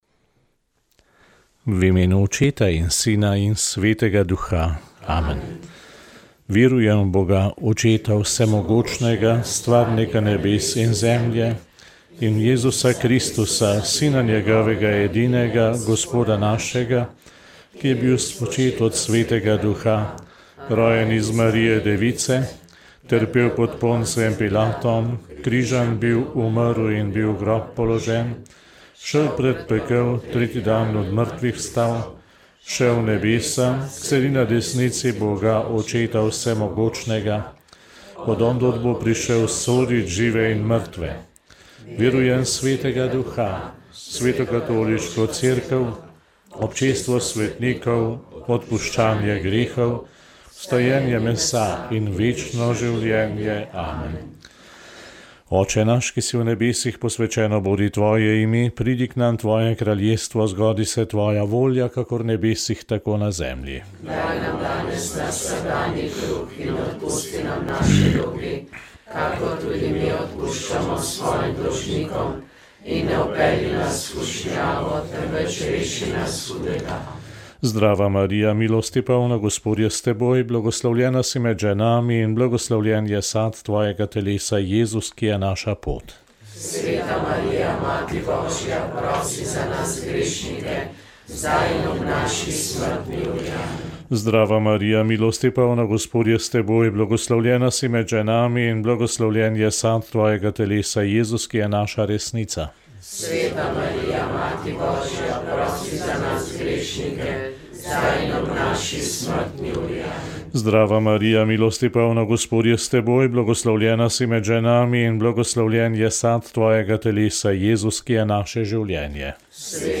Rožni venec
Molitev je vodil nadškof Stanislav Zore.